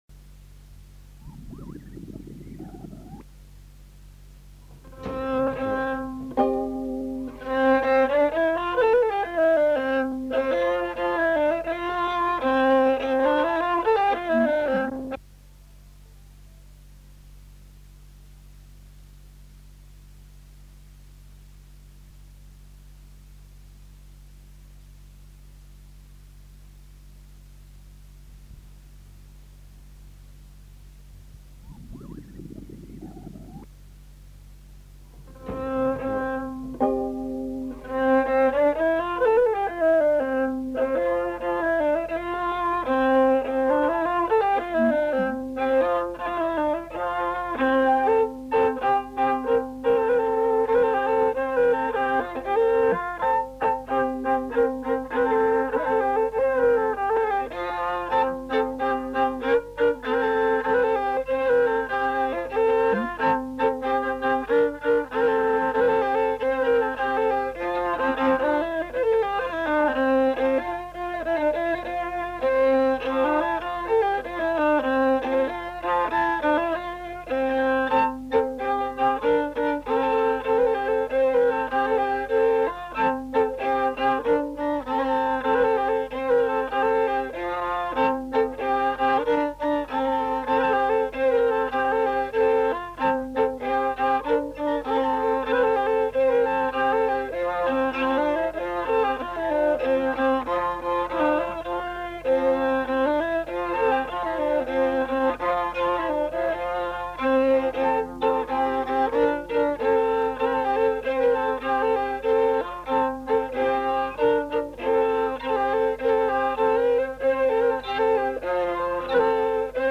Aire culturelle : Haut-Agenais
Genre : morceau instrumental
Instrument de musique : violon
Danse : rondeau